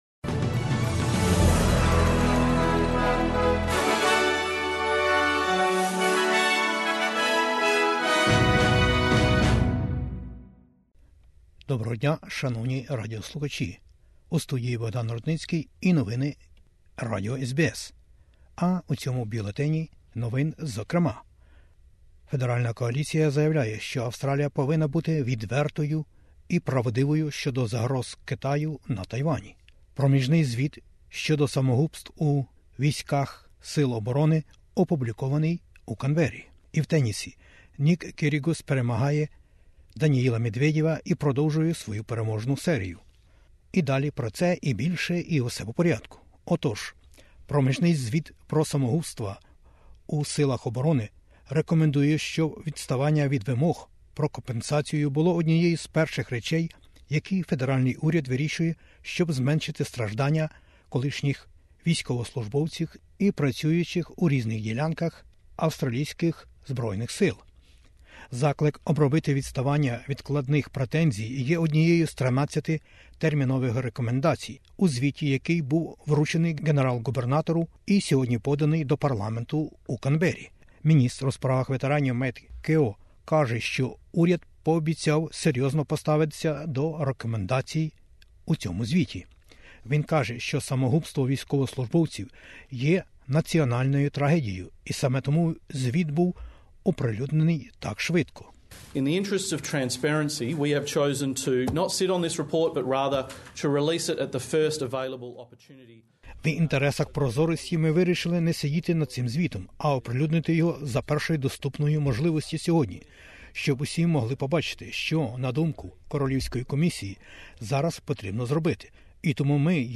SBS News in Ukrainian - 11/08/2022